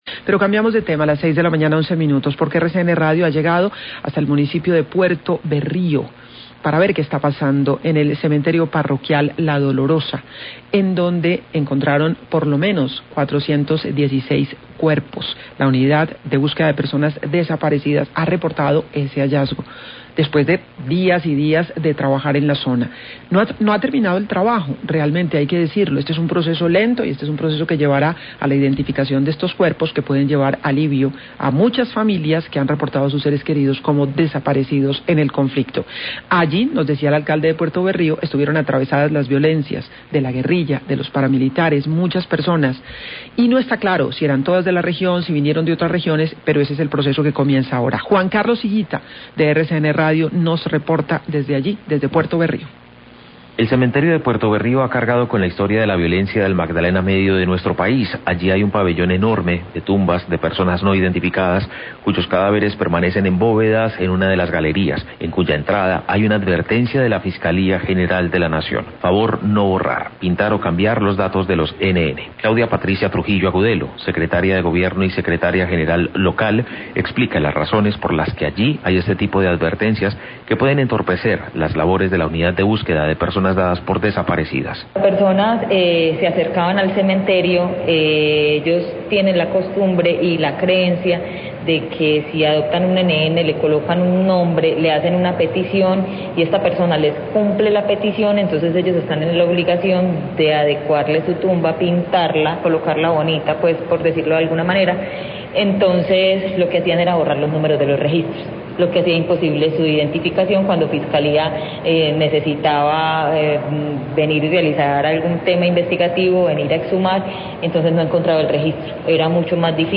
Radio
Crónica periodística sobre la costumbre de la comunidad de Puerto Berrio de "adoptar" las tumbas con cadaveres de personas desaparecidas y colocarles nombres a estas tumbas. Esto dificultaba la labor posterior de identificación de los cuerpos ya que se les había borrado el número de registro.